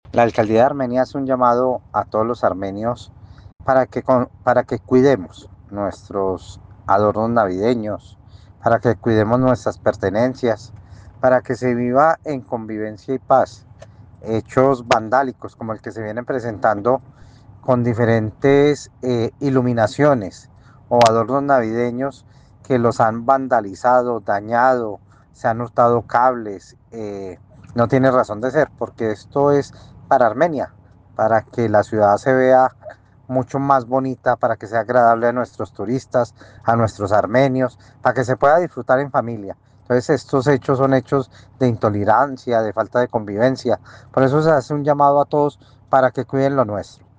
Secretario de Gobierno de Armenia